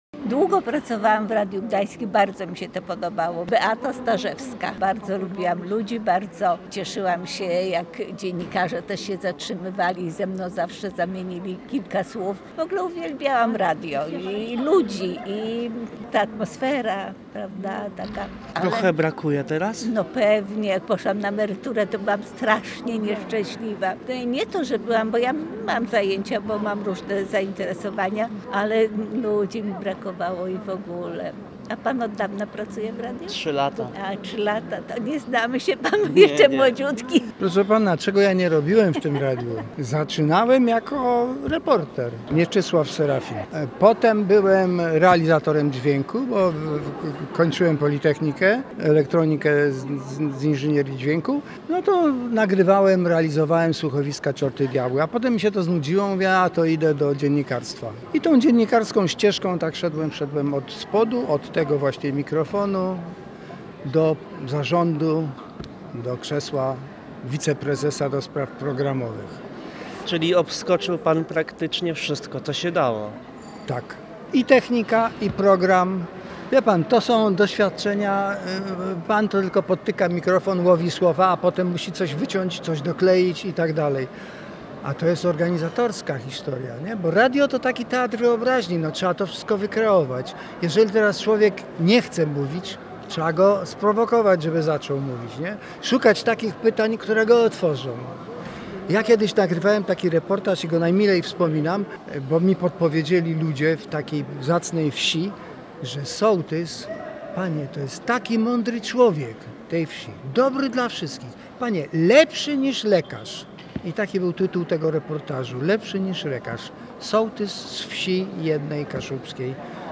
Urodziny świętujemy przez cały rok, a w piątek w Europejskim Centrum Solidarności odbyła się uroczysta gala wieńcząca nasz jubileusz.
Wśród gości nie zabrakło byłych i obecnych pracowników naszej rozgłośni, współpracowników, władz państwowych, samorządowych, przedstawicieli firm i instytucji, ale także słuchaczy, którzy są z nami od lat: